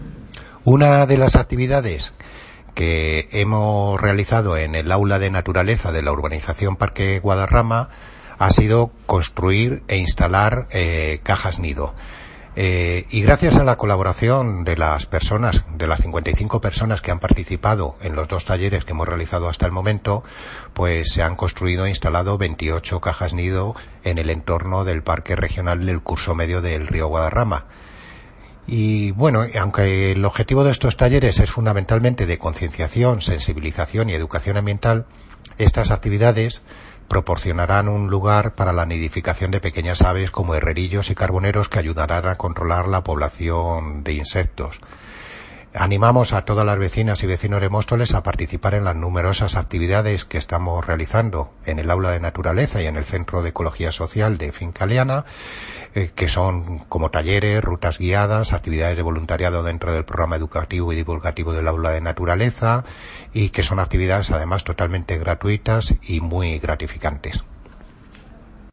Audio - Miguel Ángel Ortega (Concejal de Medio Ambiente, Parques y Jardines y Limpieza Viaria) Sobre cajas nido